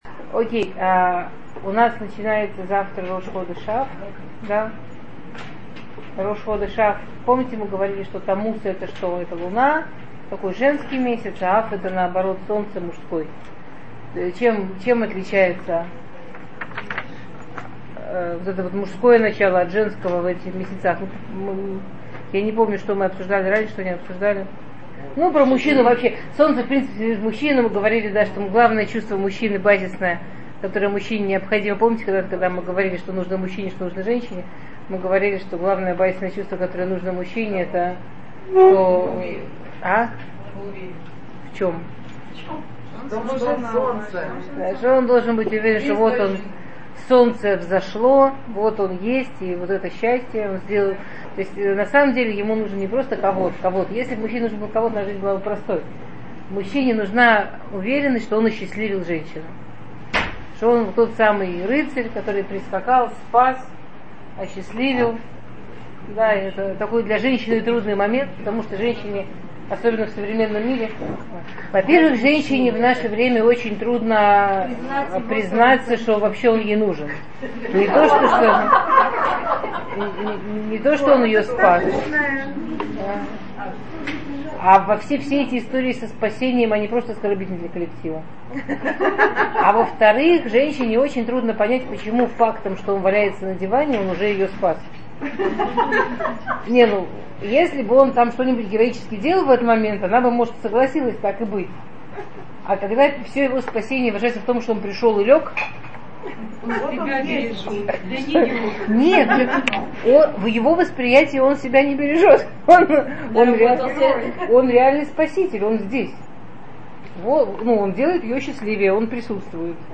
Урок перед Рош ходеш Ав.